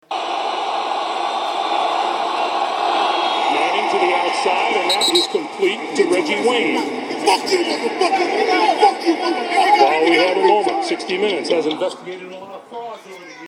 If you were watching the Texans vs Colts yesterday, you probably caught this. If you’re somewhere that foul language is frowned upon, I’d recommend not playing this. Bernard Pollard had some choice words for Reggie Wayne on the sidelines.